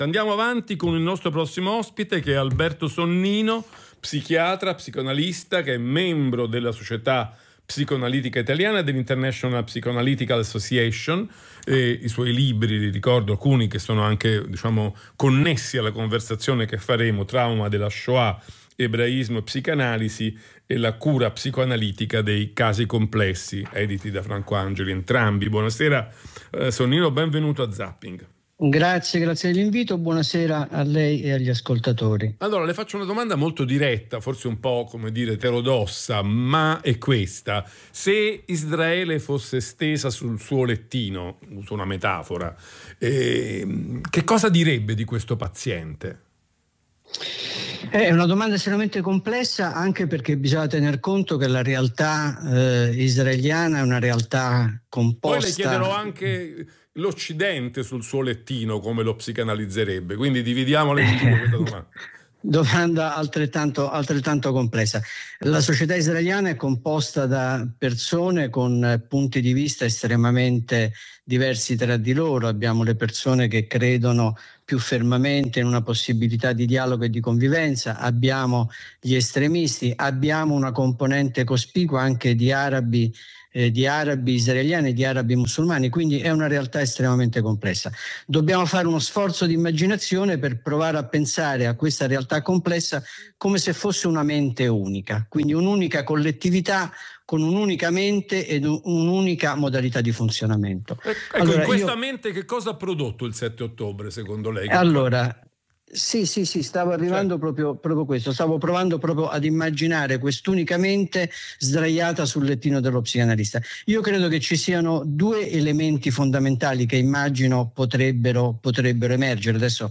La voce degli psicoanalisti